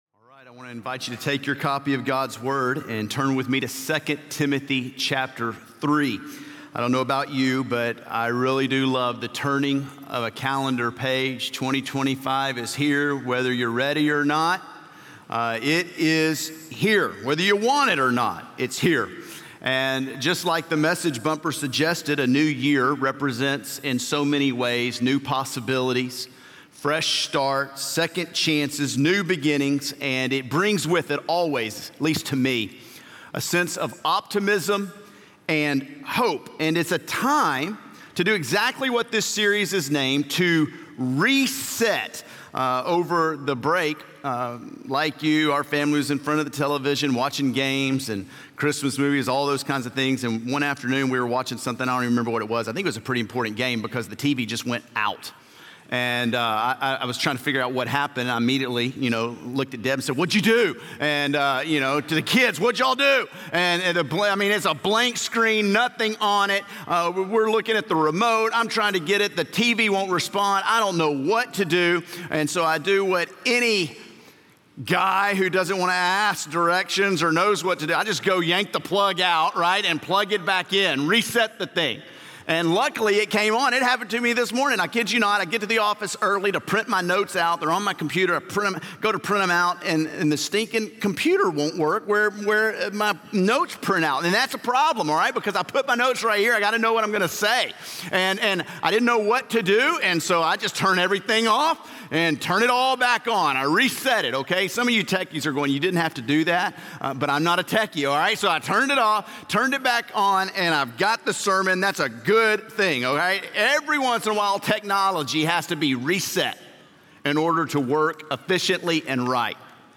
Sunday Sermons – Media Player